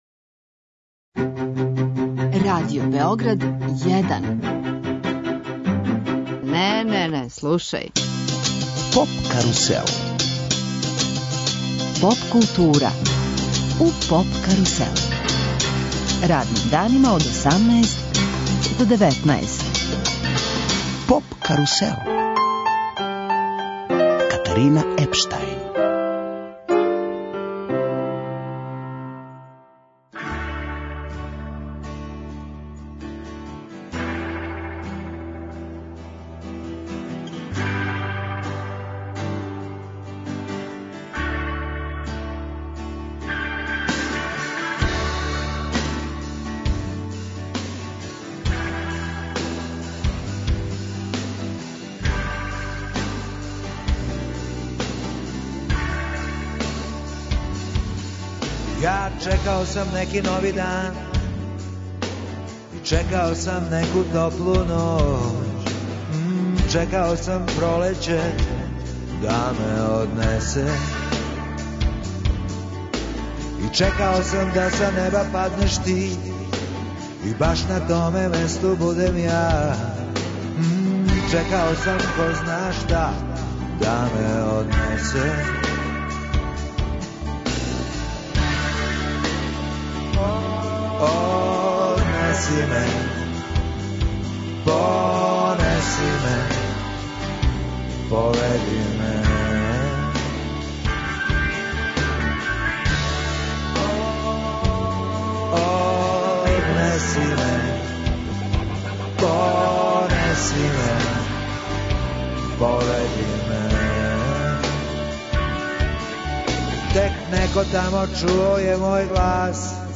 Ове две уметнице гошће су данашње емисије.